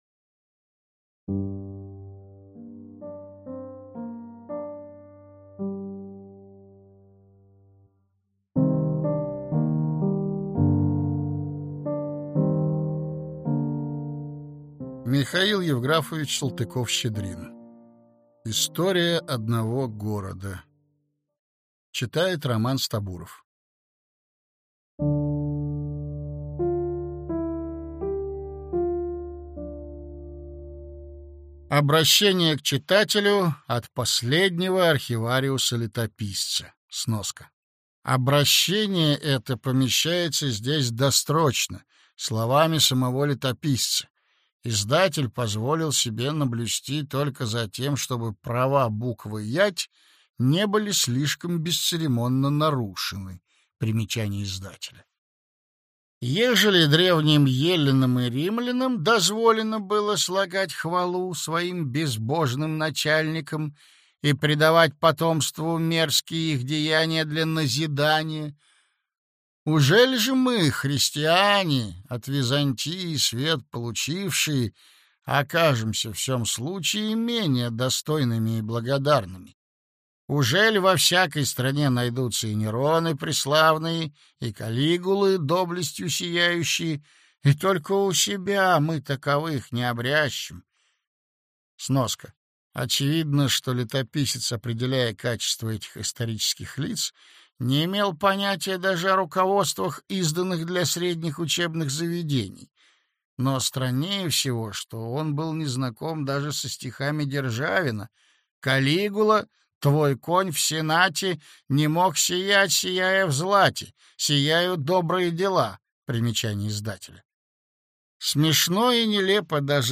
Аудиокнига История одного города | Библиотека аудиокниг